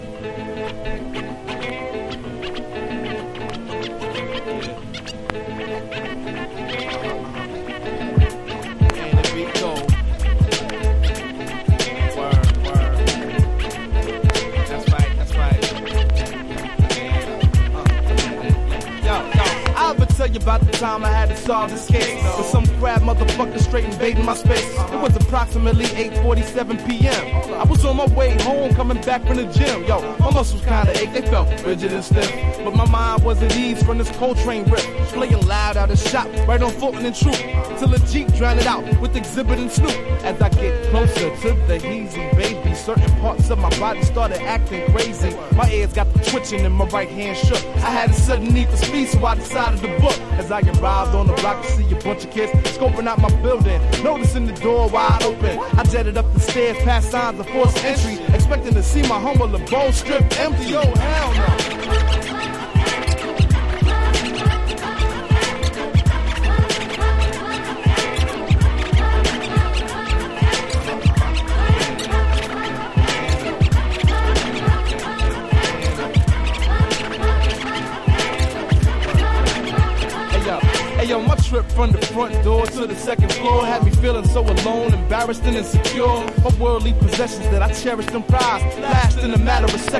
UNDERGROUND HIPHOP